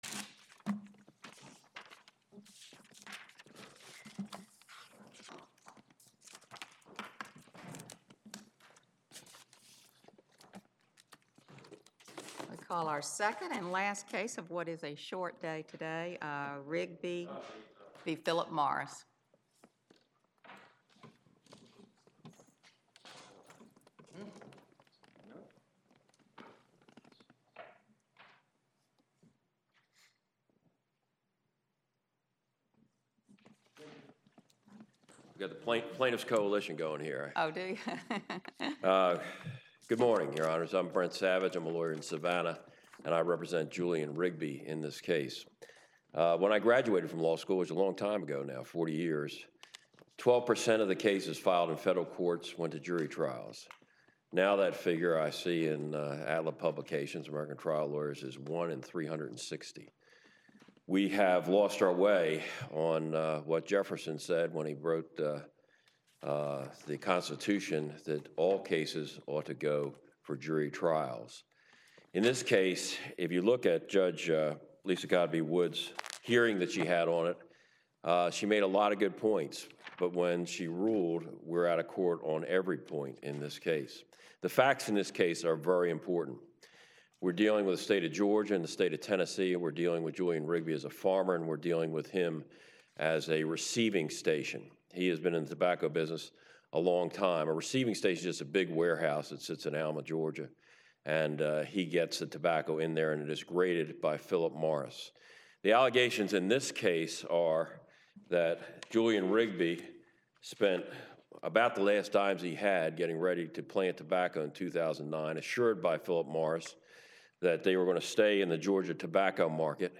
Oral Argument Recordings | United States Court of Appeals